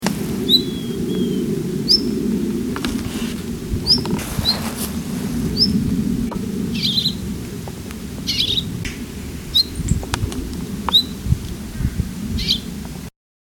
Compare the calls of one of the Lingshan birds with a bird of the ussuriensis race from Russia :
Lingshan bird (lepidus):
rosefinch-long-tailed-female-c-lingshan.mp3